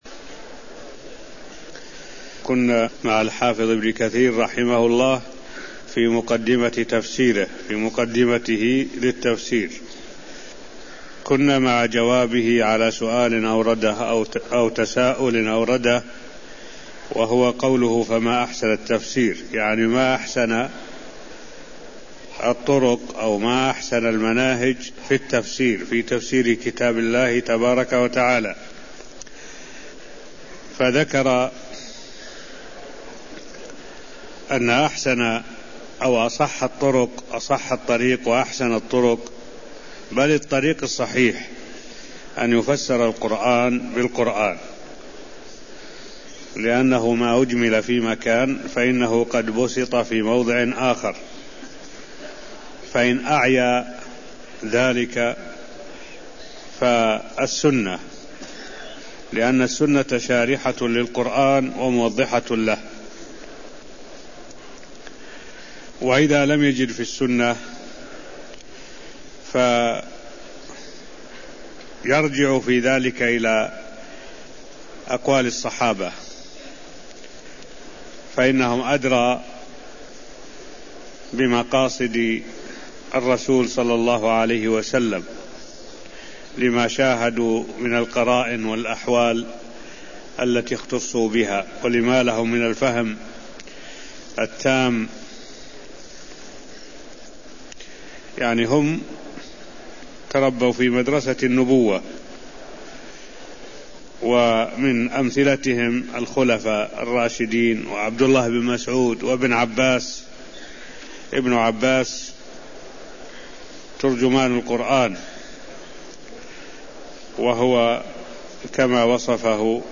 المكان: المسجد النبوي الشيخ: معالي الشيخ الدكتور صالح بن عبد الله العبود معالي الشيخ الدكتور صالح بن عبد الله العبود شرح مقدمة ابن كثير (0002)